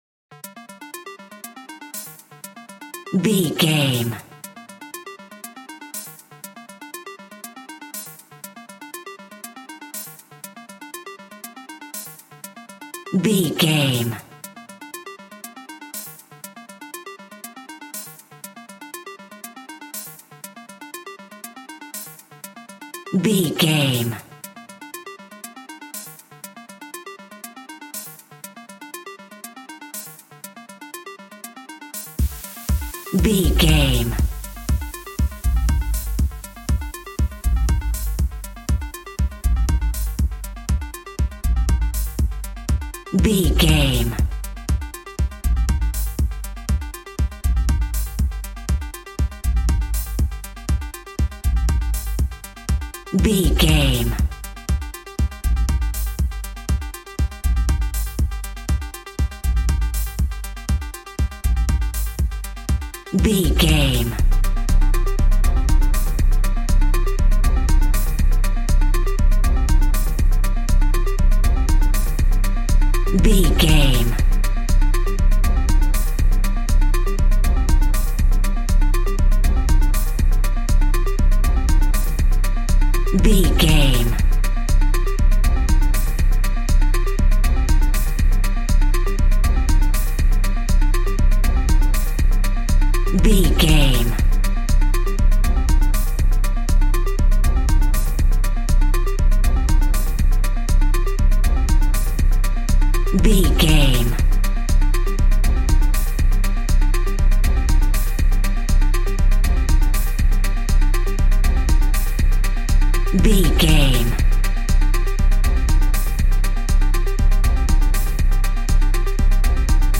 Epic / Action
Fast paced
Aeolian/Minor
Fast
dark
futuristic
groovy
aggressive
synthesiser
drums
drum machine
vocals
house
electro dance
techno
trance
synth leads
synth bass
upbeat